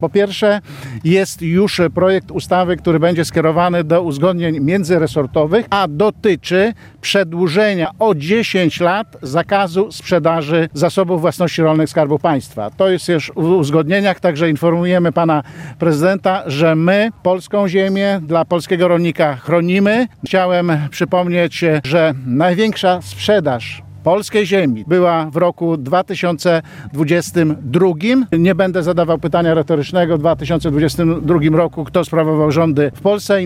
Wiceminister rolnictwa Jacek Czerniak podkreślał podczas Dni Konia Arabskiego w Janowie Podlaskim, że resort podejmuje działania mające na celu ochronę polskiego rolnika.